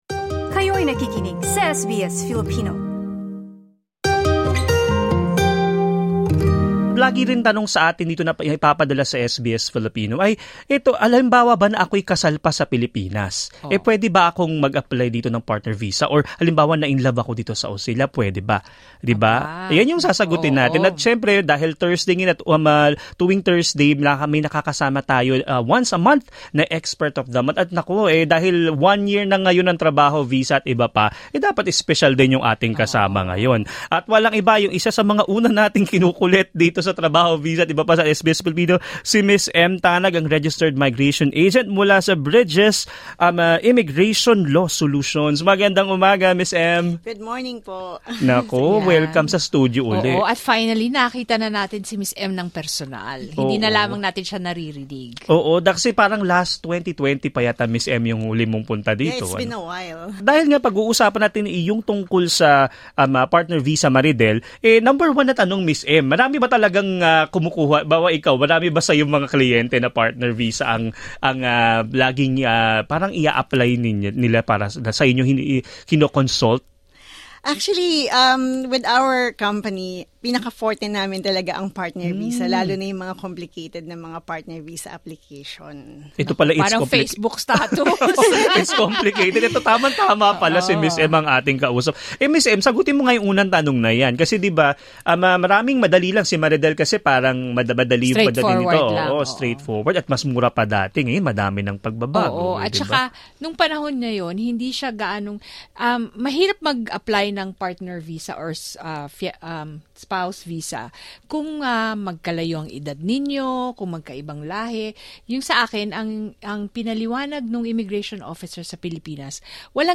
SBS Filipino 24:17 Filipino In an interview with SBS Filipino